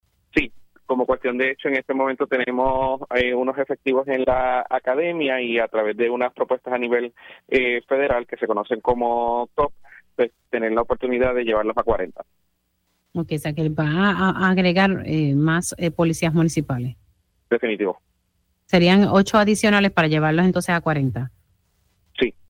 115-MARCOS-CRUZ-ALC-VEGA-BAJA-AGENTES-MUNICIPALES-NO-DA-BASTO-ANTE-ALZA-CRIMINAL-AUMENTARA-POLICIAS-MUNICIPALES.mp3